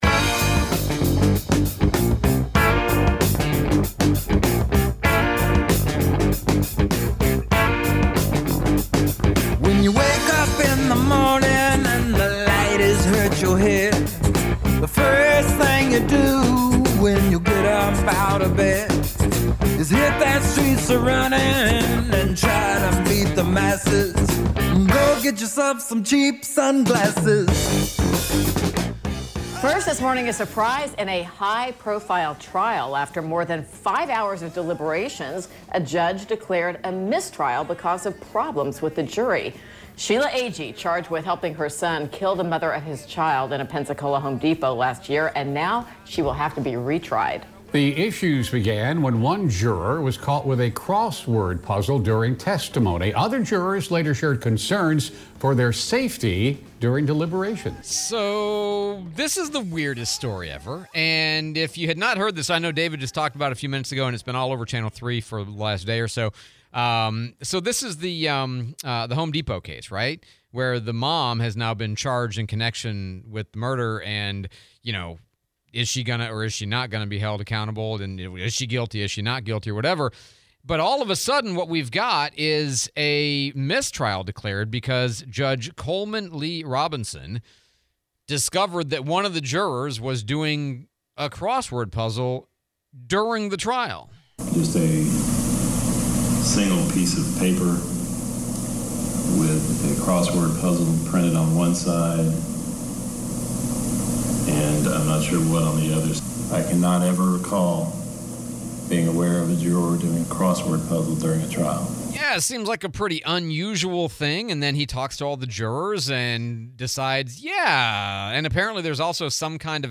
Home Depot Murder Case leads to mistrial due to crossword puzzle / Interview